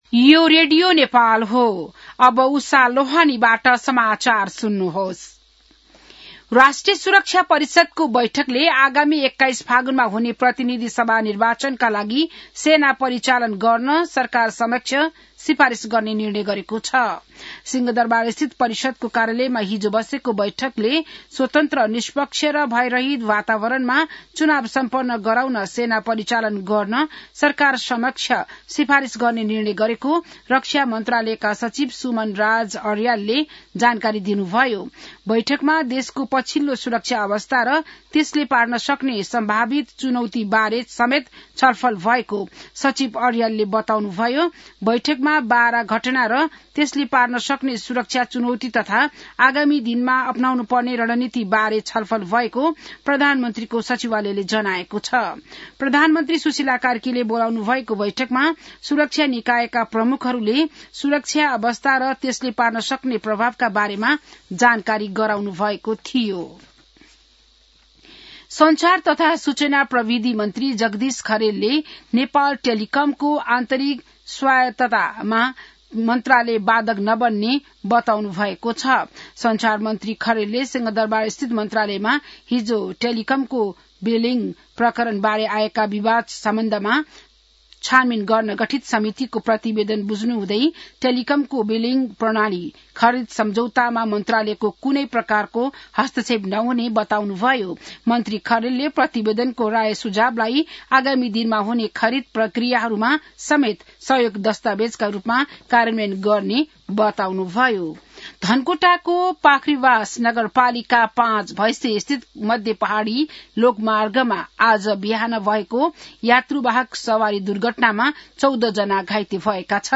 बिहान १० बजेको नेपाली समाचार : ५ मंसिर , २०८२